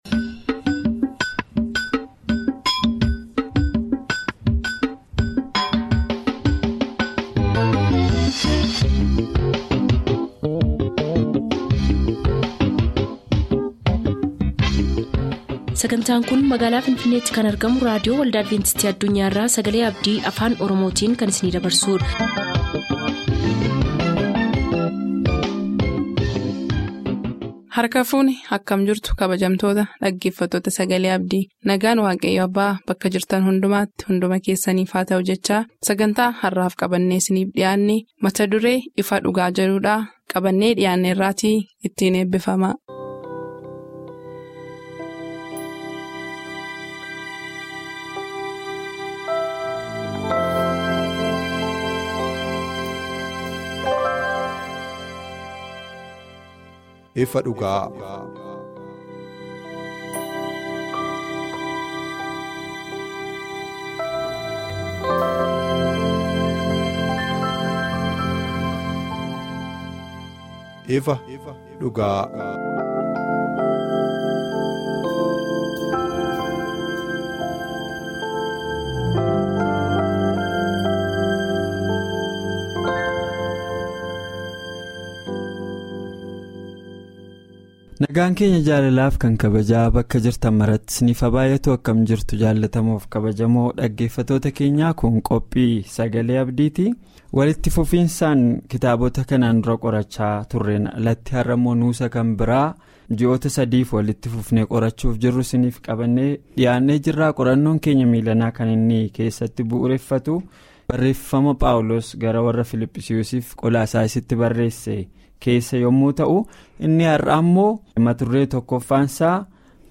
AND TODAY39;S SERMONBy Adventist World Radio … continue reading